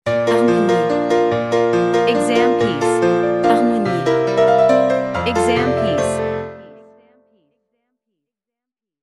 • 人声数拍
我们是钢琴练习教材专家